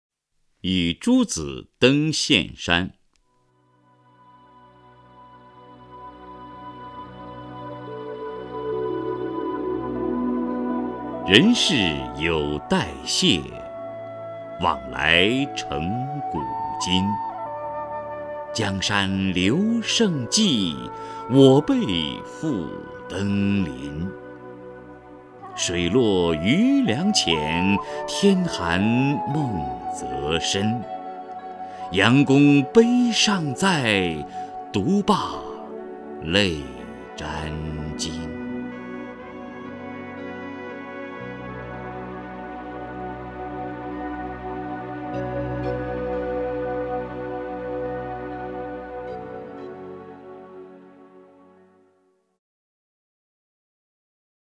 首页 视听 名家朗诵欣赏 王波
王波朗诵：《与诸子登岘山》(（唐）孟浩然)